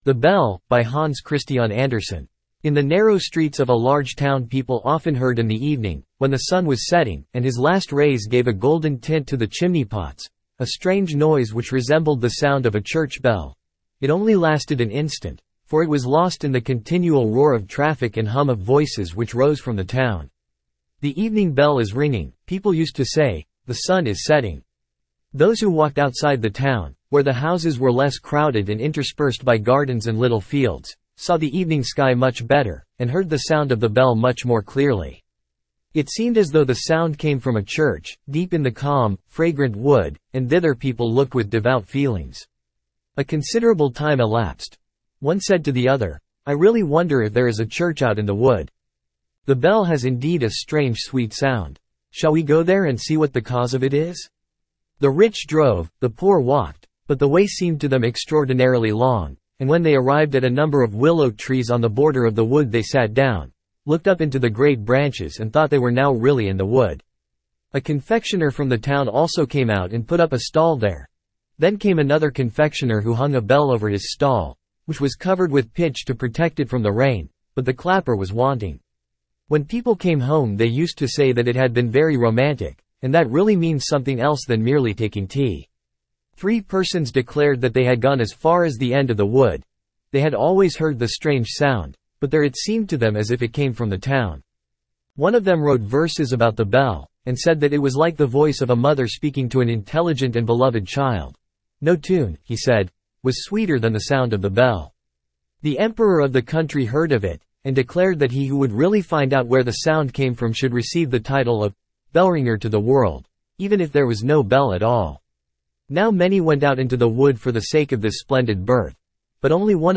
Standard (Male)